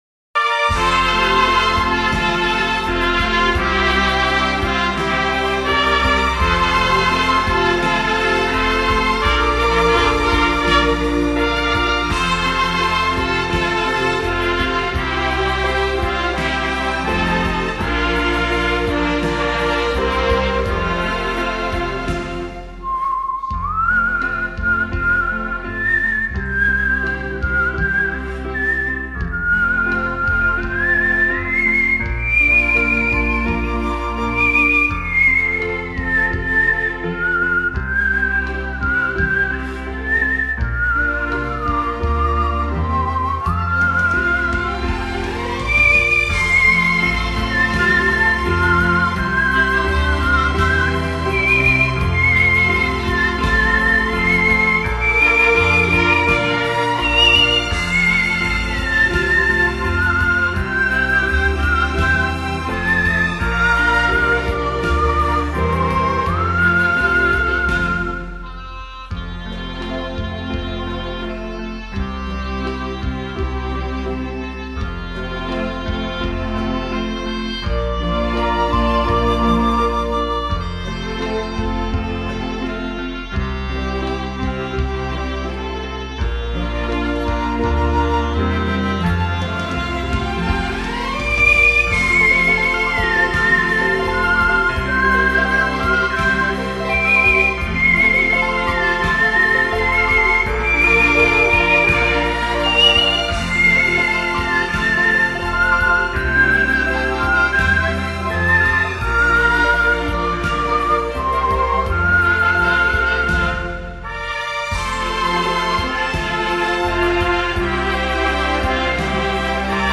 最动听的口哨音乐
最朴素的声音带来了一股清凉的风
透明、神秘的口哨声充满了朦胧的氛围
为人们带来内心的平静